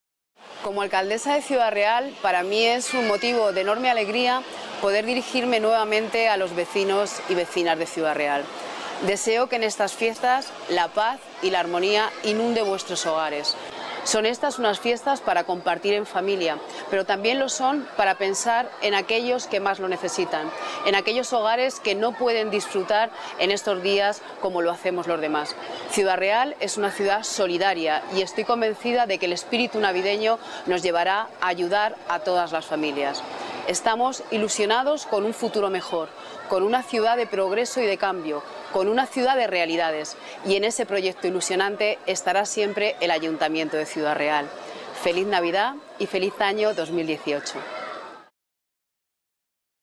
Mensaje de Navidad de la Alcaldesa de Ciudad Real, Pilar Zamora
audio_alcaldesa_saluda_navidad.mp3